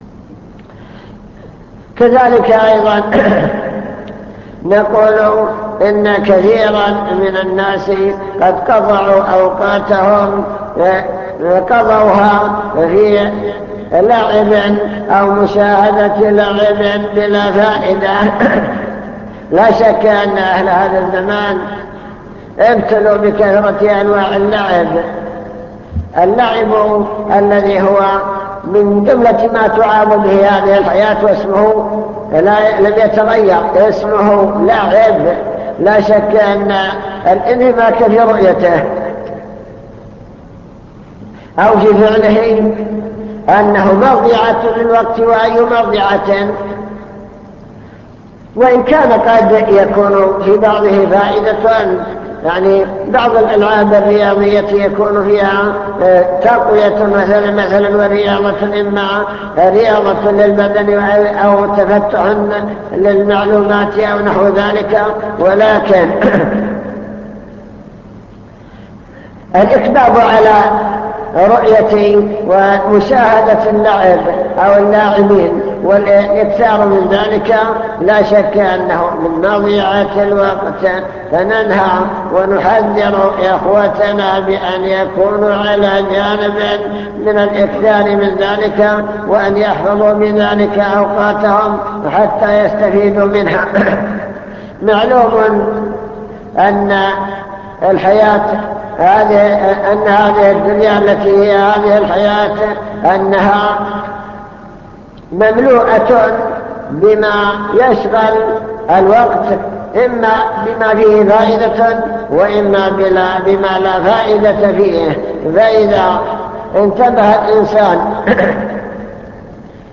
المكتبة الصوتية  تسجيلات - محاضرات ودروس  محاضرة بعنوان الشباب والفراغ التحذير من إضاعة الوقت